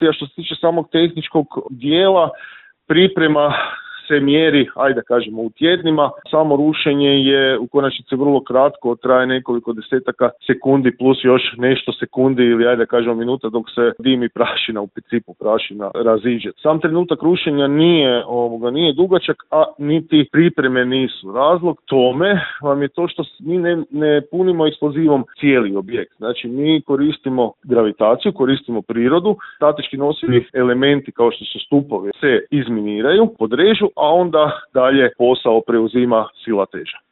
razgovarali smo u Intervjuu Media servisa